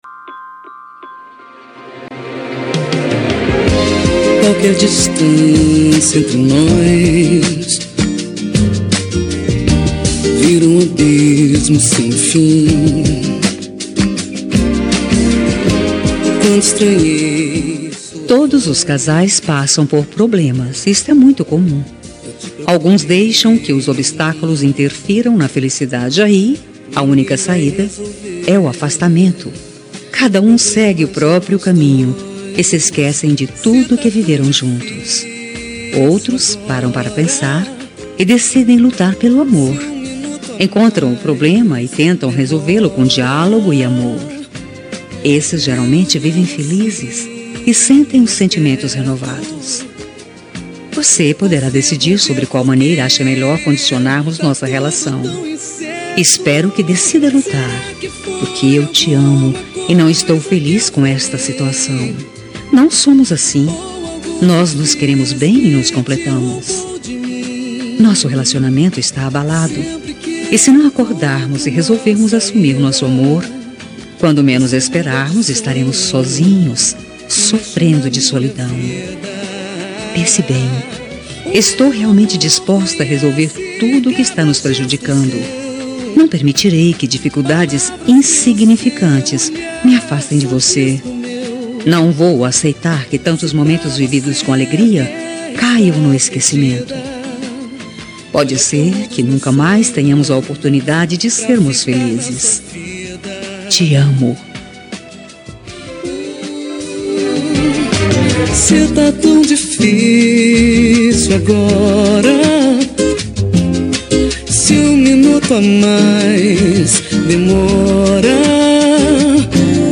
Toque para Não Terminar – Voz Feminina – Cód: 202098